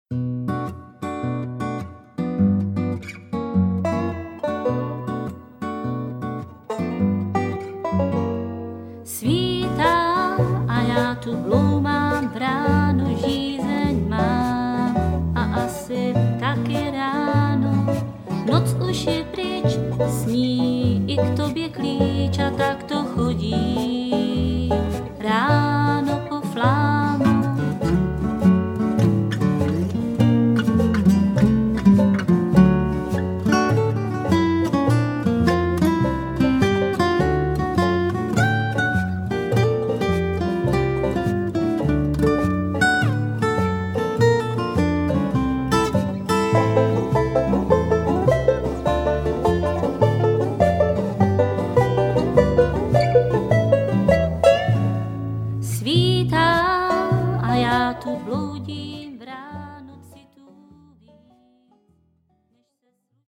Recorded in February and March 2008 in Olomouc
banjo, guitar, harmony vocals
guitar, fiddle, lead nad harmony vocals
upright bass, lead and harmony vocals